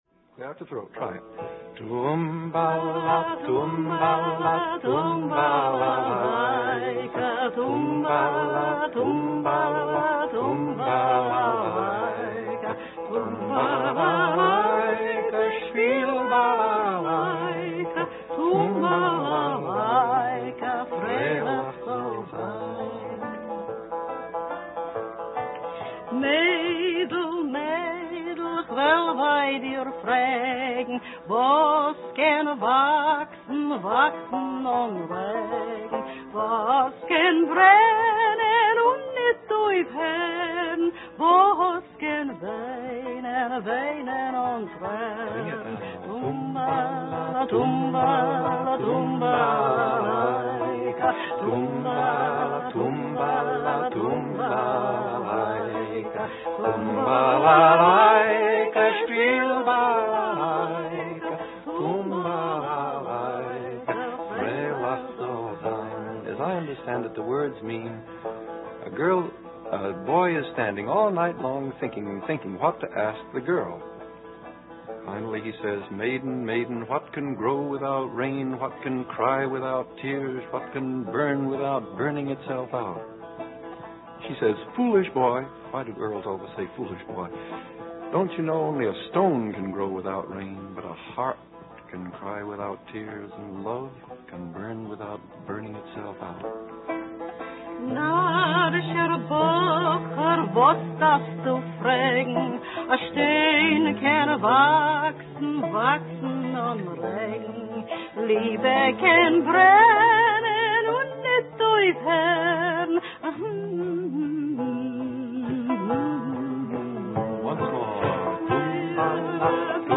По своему обыкновению, Пит Сигер иногда прерывает пение и комментирует песню, переводя её текст на английский язык. Звучат второй и третий куплеты:
Аудиофрагмент из телепередачи